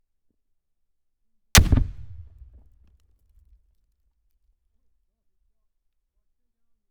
L109A1-HE GRENADES
Here’s some fragmentation grenades being thrown into a stone target area (the background of the photo above), I was able to get the mics pretty close for this and wasn’t afraid about losing any if they were too close.
DPA 4062 at 10m
Tasty crushed transients and fast follow through of bass, with very little environment which means great for sound design.  High frequencies a bit closed down due to being 10m from source.